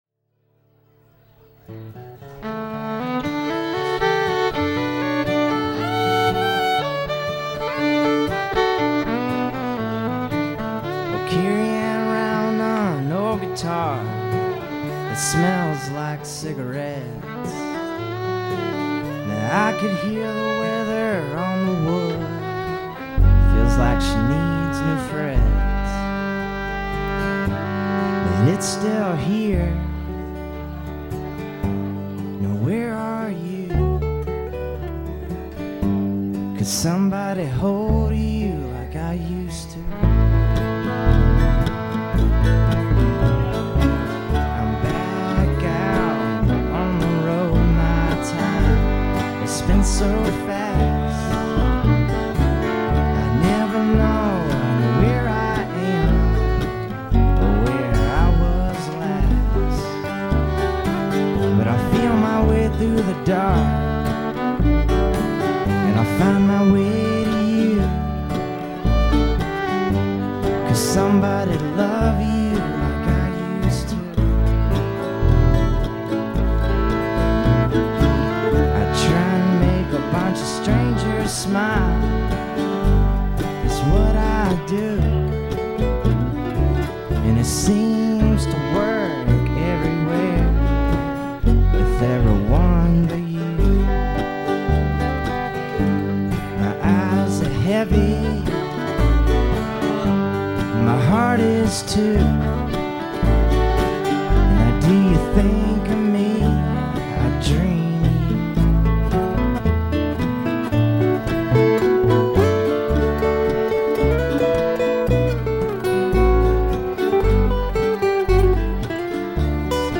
Guitar, Vocals
Bass, Vocals
Fiddle, Background Vocals
Mandolin, Background Vocals